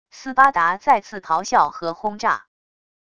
斯巴达再次咆哮和轰炸wav音频